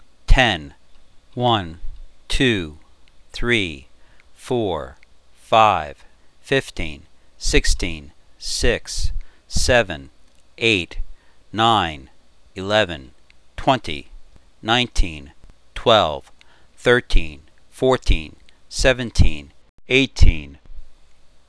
Same count, numbers rearranged
Note: In rearranged count, final number (18) softened by lowering pitch and applying a noisegate filter to the first syllable to make it sound more natural as the final number in the series